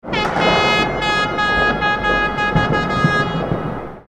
Festive Air Horn Celebration Sound Effect
This festive air horn celebration sound effect adds instant energy and excitement. It fits street celebrations with fireworks, firecrackers, joy, and air horns.
Festive-air-horn-celebration-sound-effect.mp3